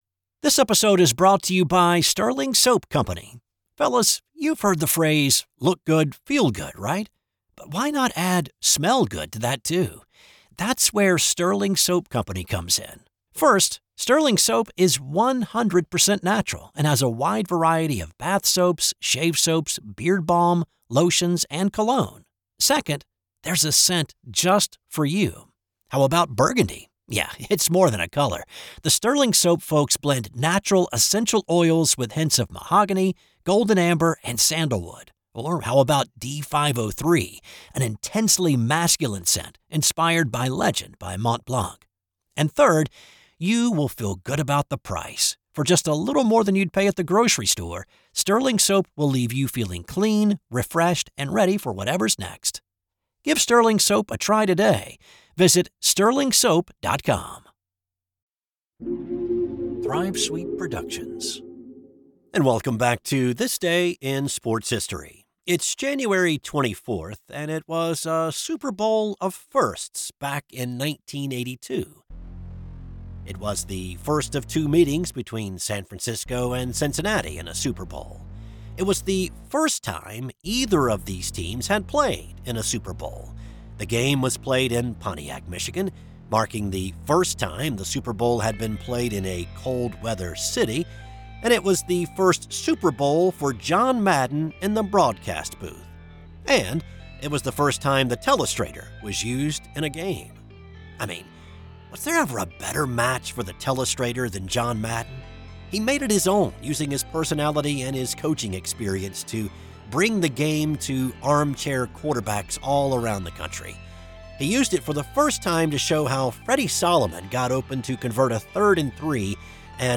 'This Day in Sports History' is a one person operation.